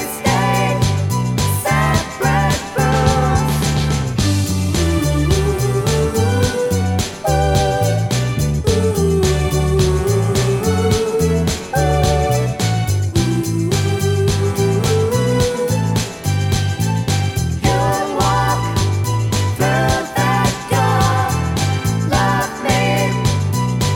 Two Semitones Down Soul / Motown 2:51 Buy £1.50